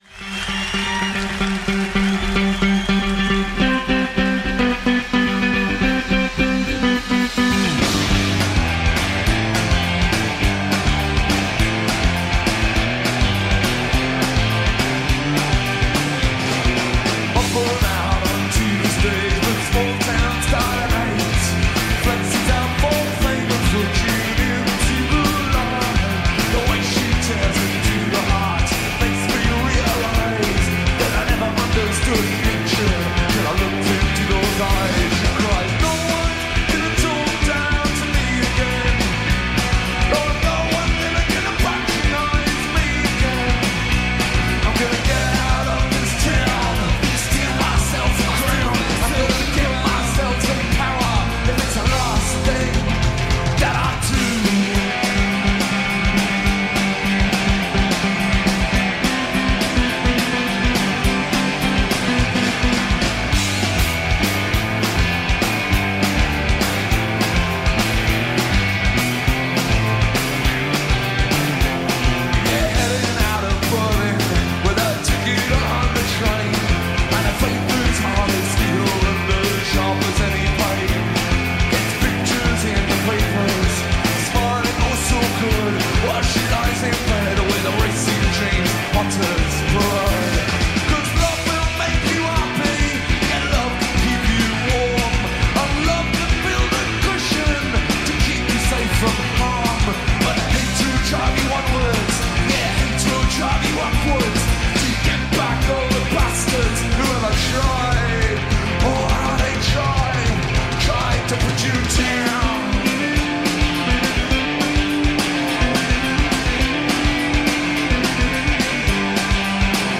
in concert at Berlin Eissporthalle